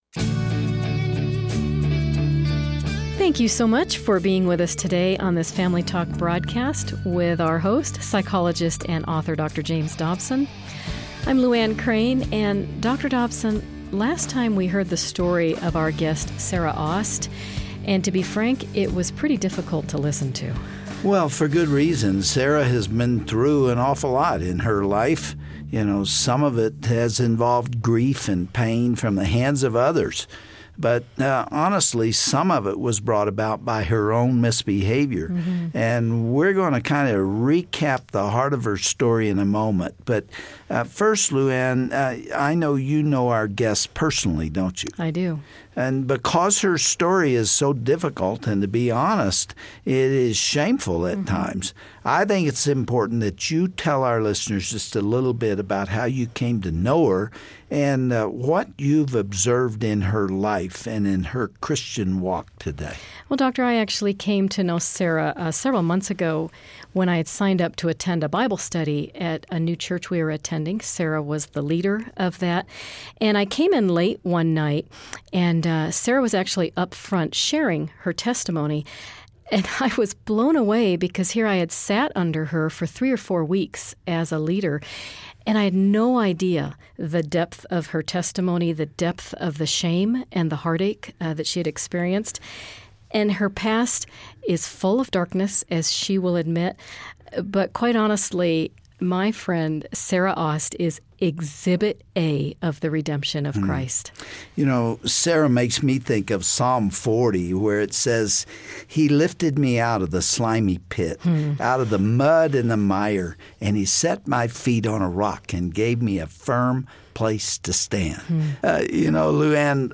On today's program, we'll hear from a woman who wallowed in the muck for years as a result of the abuse she endured in childhood. She now boldly declares that God has lifted her from the pit and set her feet on solid ground.